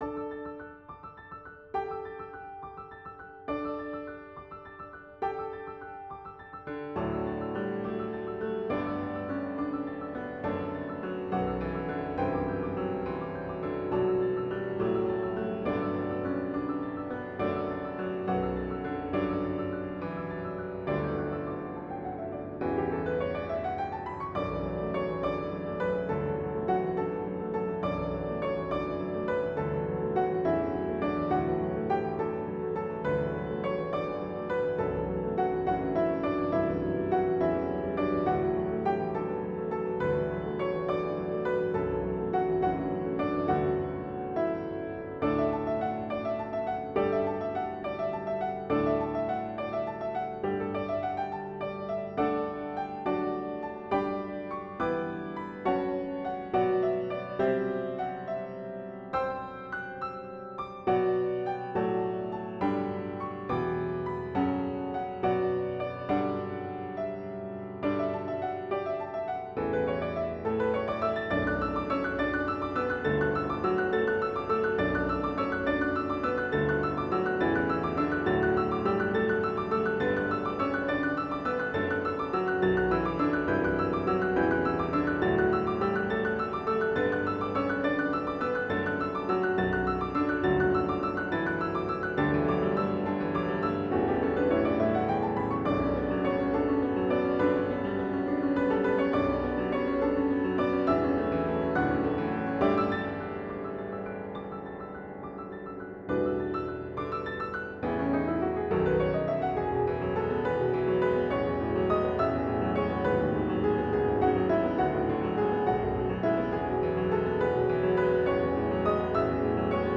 Piano Duet
Hymn Arrangement